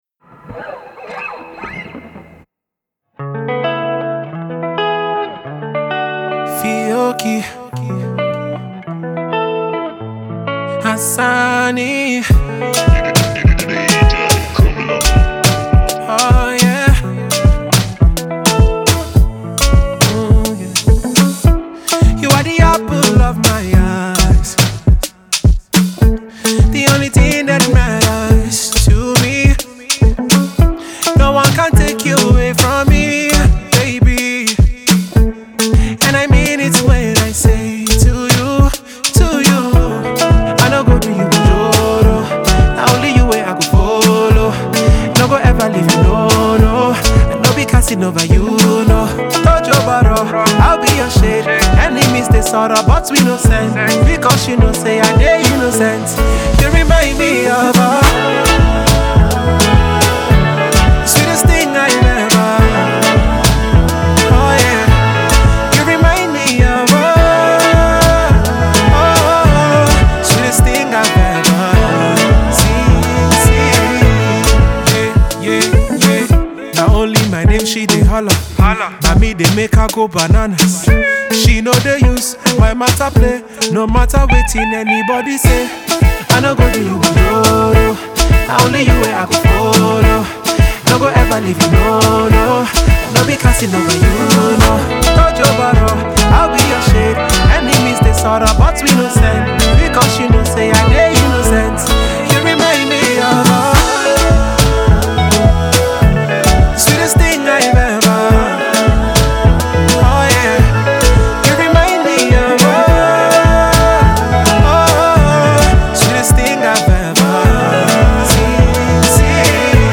songwriter and singer
guitarist